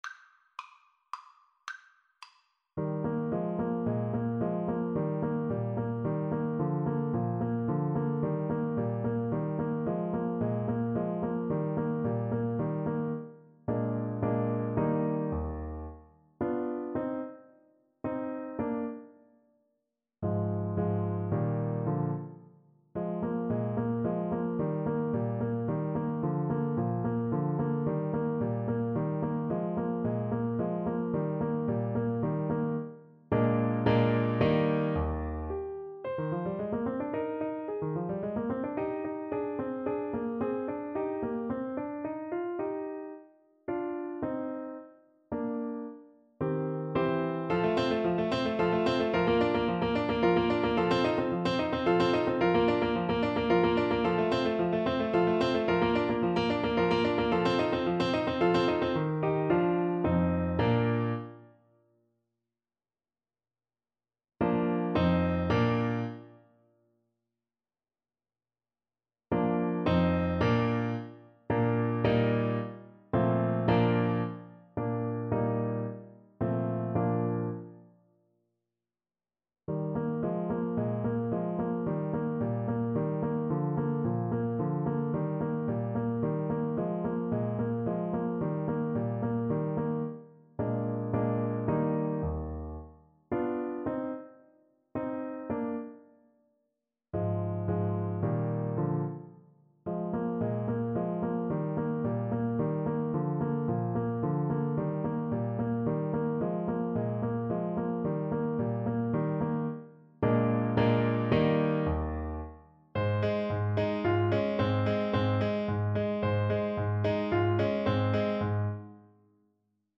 3/4 (View more 3/4 Music)
Classical (View more Classical Saxophone Music)